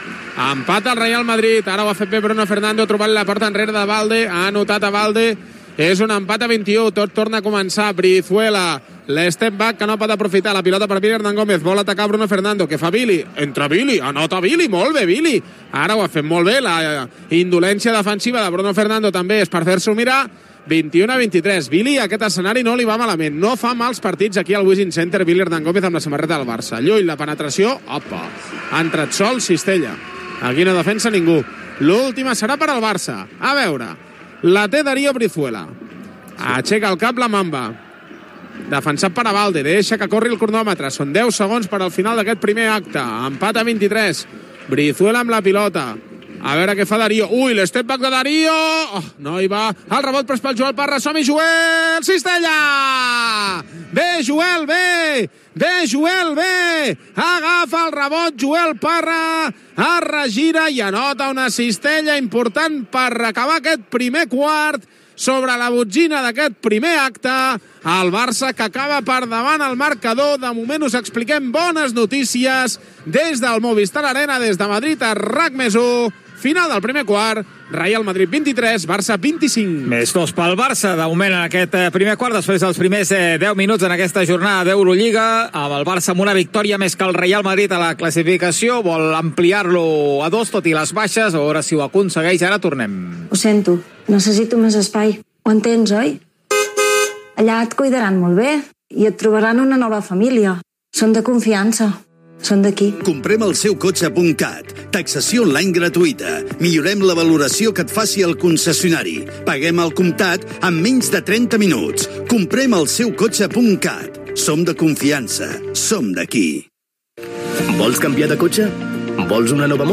Transmissió del parit de l'Eurolliga de Bàsquet masculí entre el Real Madrid i el F.C. Barcelona. Narració dels minuts finals del primer quart, publicitat, indicatiu, narració de les primeres jugades del segon quart
Esportiu